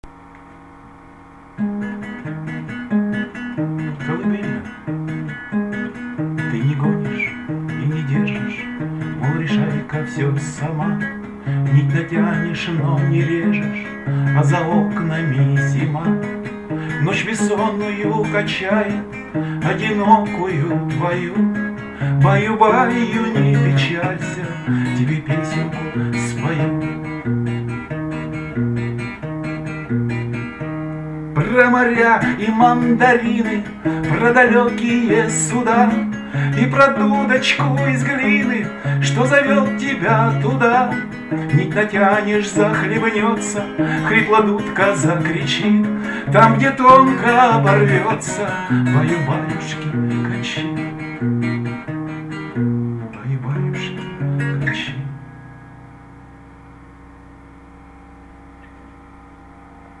Колыбельная